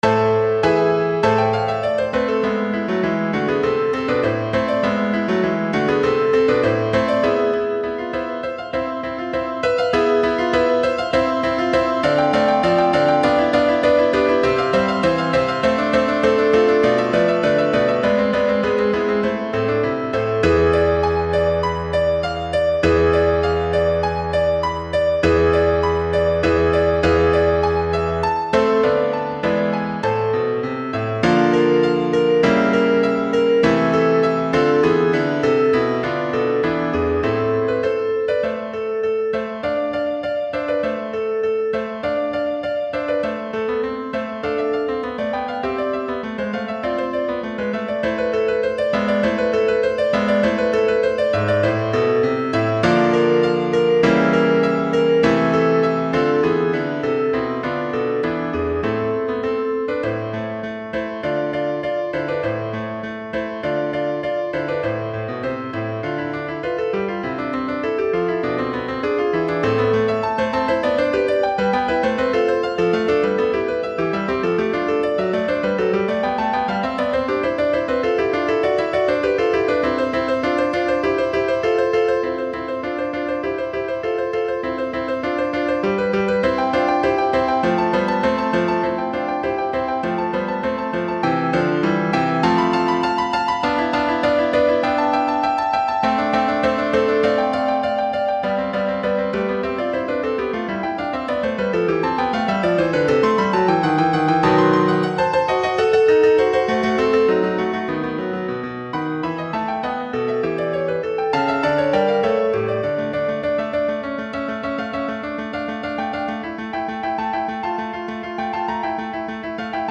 Instrument: Organ
Style: Classical
organ-concerto-in-a-minor-bwv-593.mp3